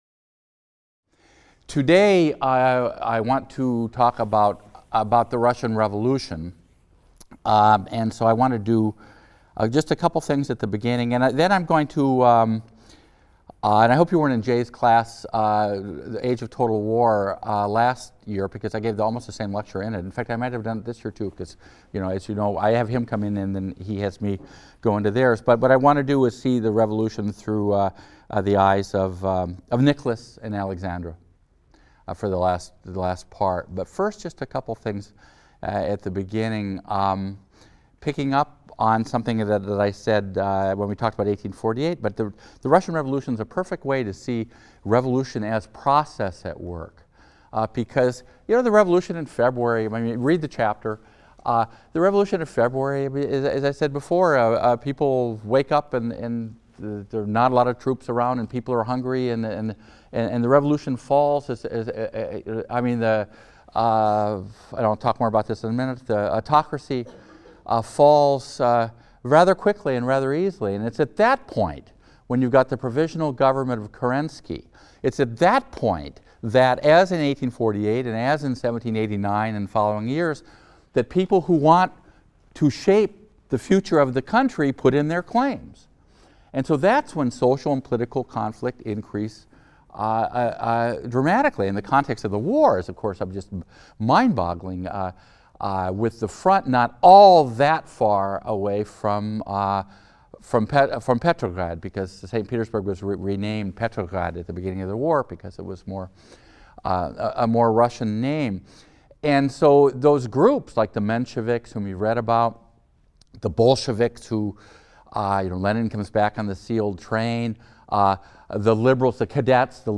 HIST 202 - Lecture 19 - The Romanovs and the Russian Revolution | Open Yale Courses